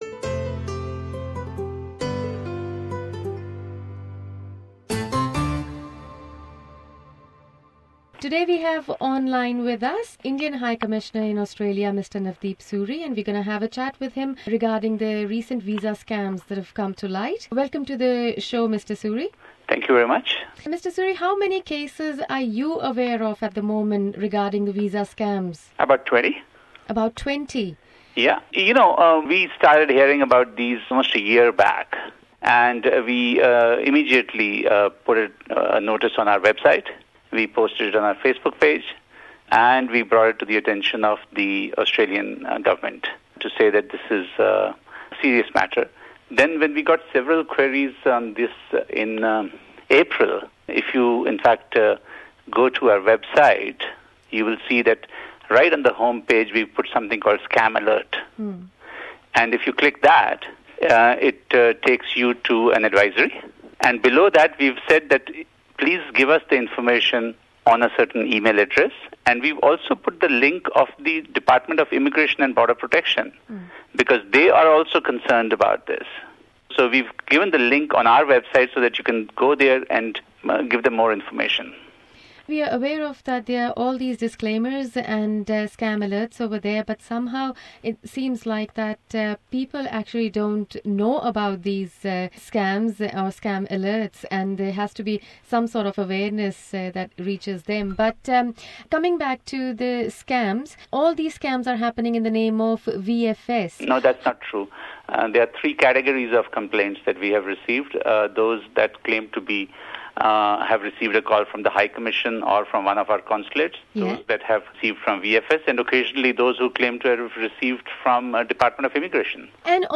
SBS Punjabi contacted the Indian High Commissioner Mr Navdeep Suri to seek some answers in this matter.